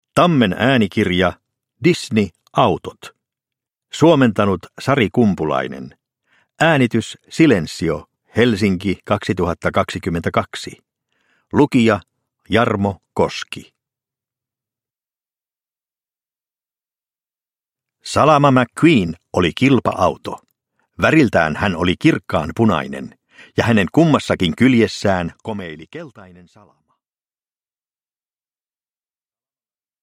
Pixar. Autot – Ljudbok – Laddas ner